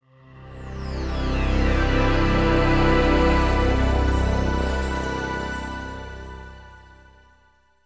• Качество: высокое
Звук загрузки Windows 98